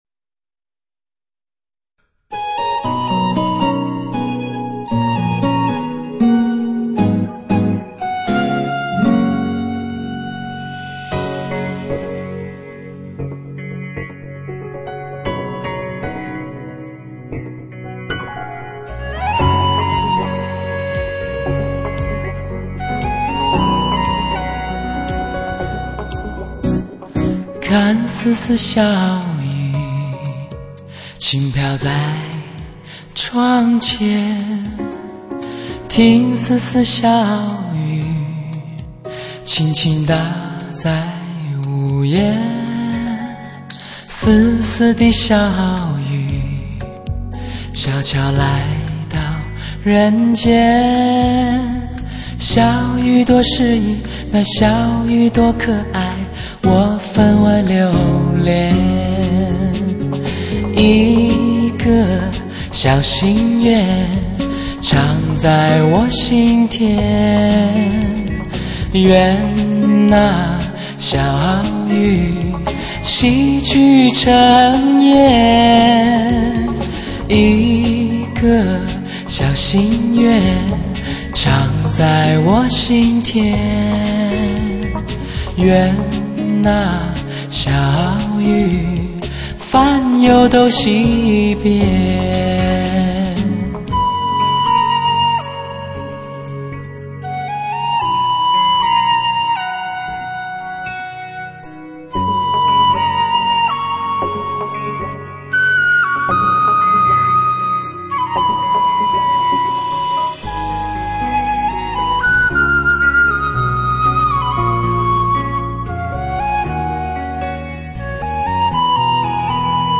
[center]一张不可错过的无比比拟的磁性男声[center]
大胆创新的编曲    融入新的音乐元素    恰当的乐器烘托
感性男声轻松惬意地唱出了内心深处浓浓的情感，令人如痴如醉……